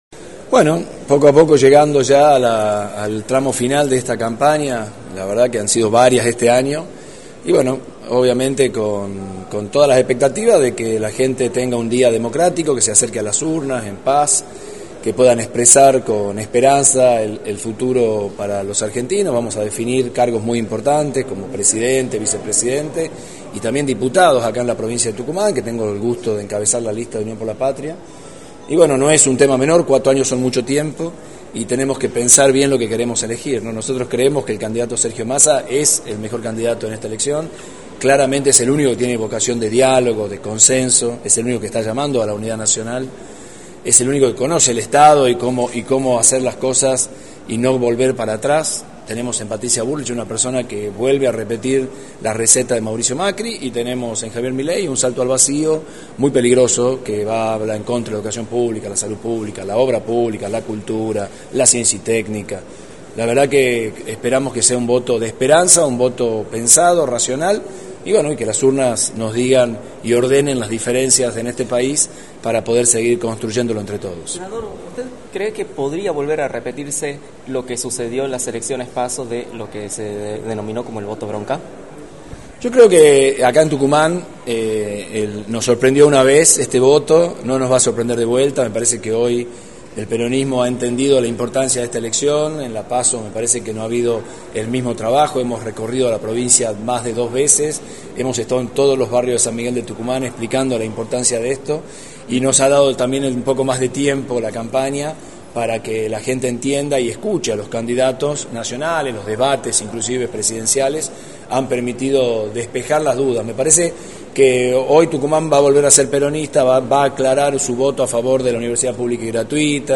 Pablo Yedlin, candidato a Diputado Nacional por Unión por la Patria, participó de las actividades por el Día de la Lealtad Peronista y remarcó en Radio del Plata Tucumán, por la 93.9, cuáles son sus expectativas para el domingo.
«Sergio Massa es el mejor candidato, es el único que tiene vocación de diálogo y consenso, es el único que llama a la unidad nacional y que conoce el Estado, esperamos que sea un voto de esperanza y racional» señaló Pablo Yedlin en entrevista para «La Mañana del Plata», por la 93.9.